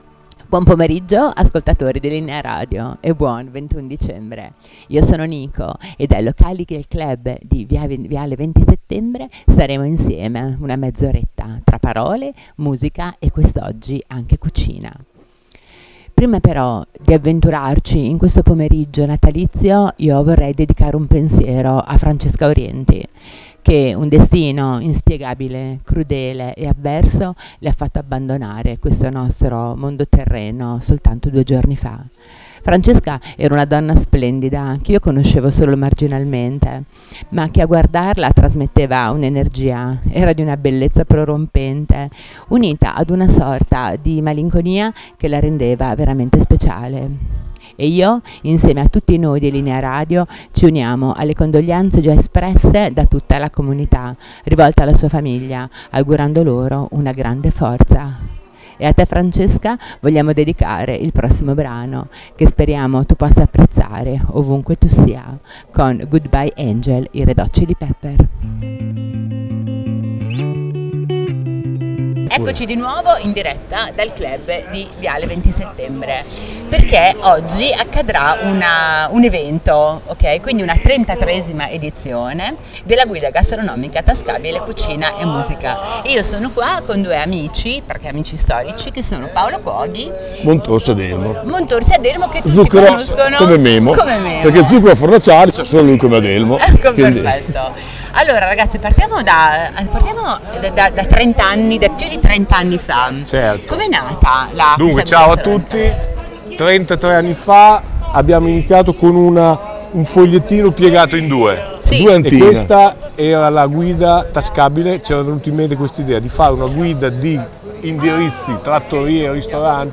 Diretta Linea Radio dal Clhub di viale XX Settembre a Sassuolo